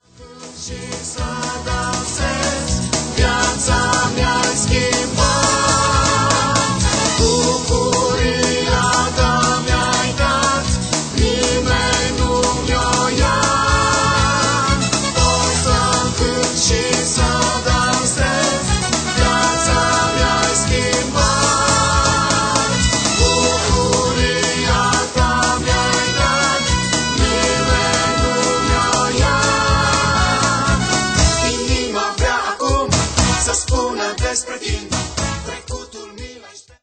Un alt album de lauda si inchinare